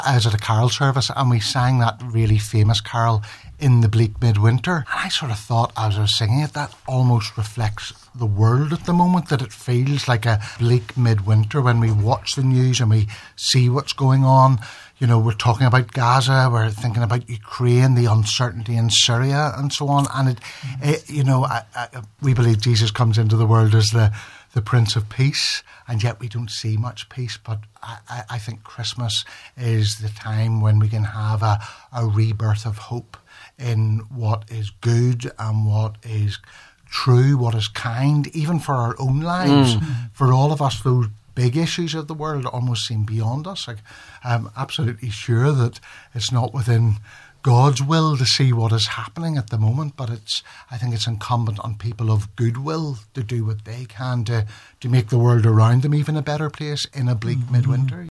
Bishops stress the true meaning of the season in special Christmas Eve broadcast